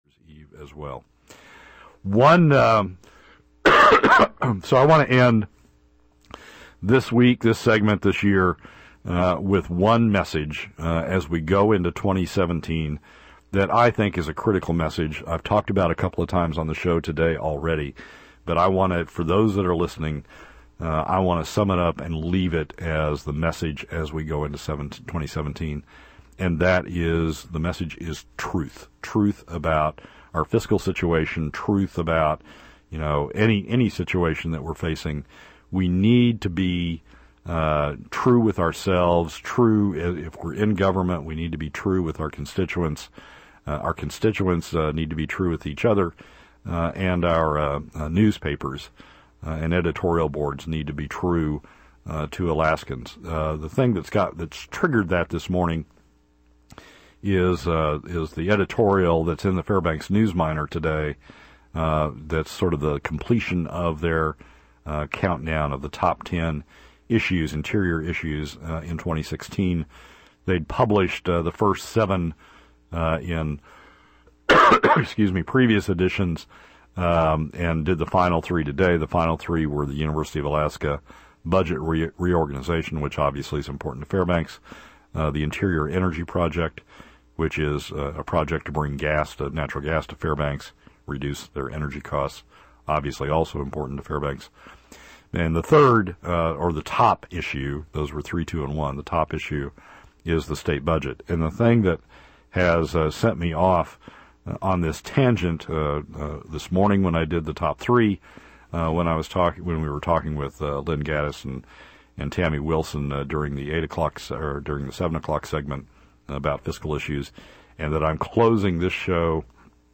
For those interested, here is the portion from the earlier “Top 3” block, and then the final wrap up on the issue in my final segment of the year.